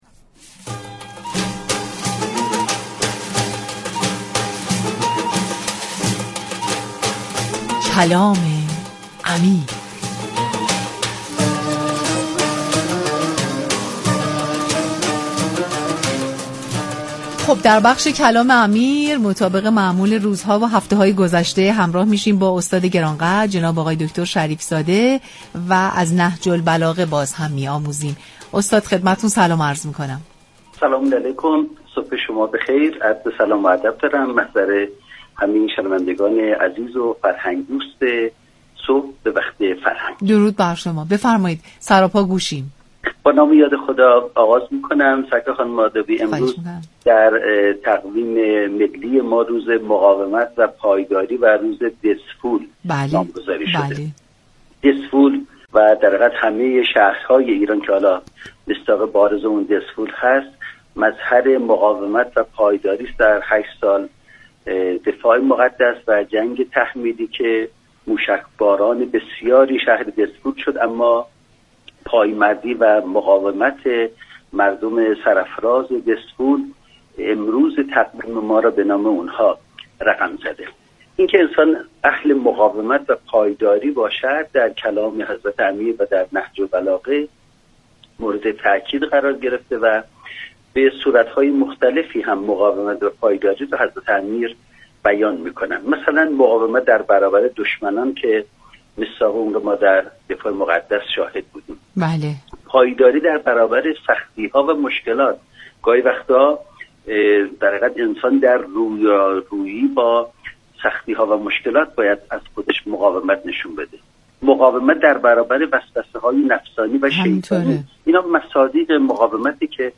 در گفت‌وگو با برنامه «صبح به وقت فرهنگ» از رادیو فرهنگ